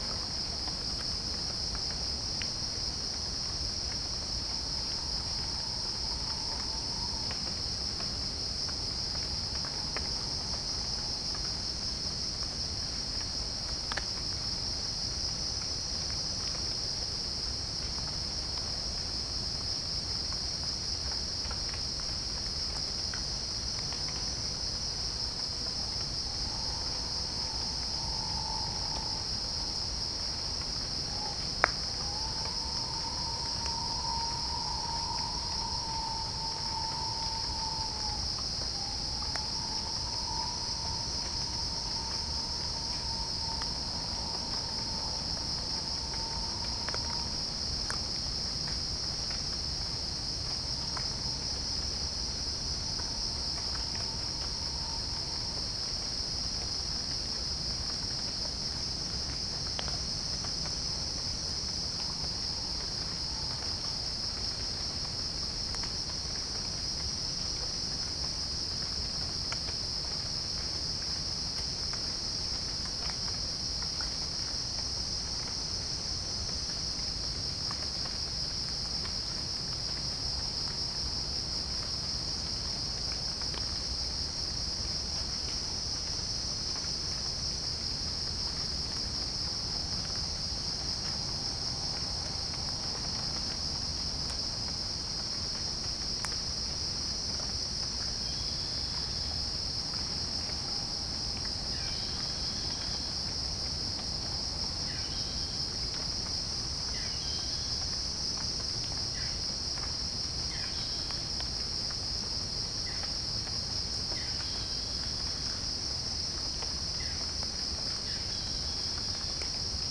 Geopelia striata
Orthotomus sericeus
Pycnonotus goiavier